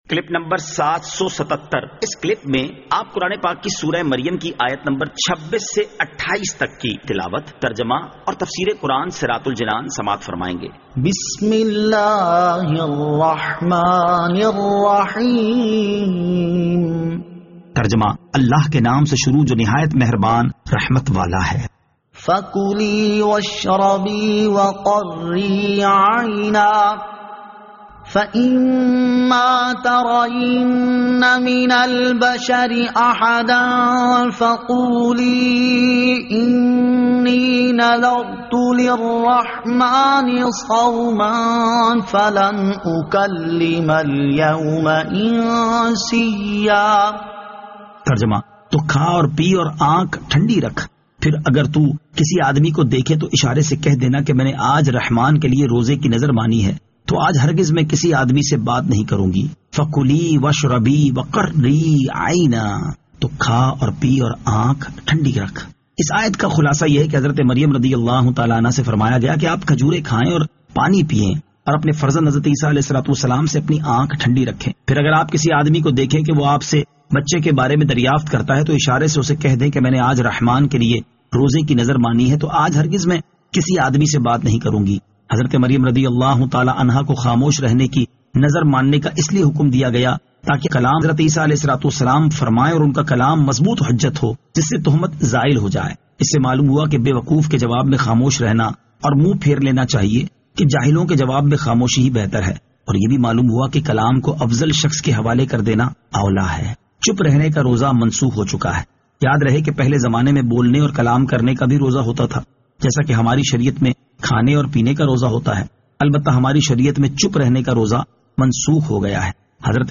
Surah Maryam Ayat 26 To 28 Tilawat , Tarjama , Tafseer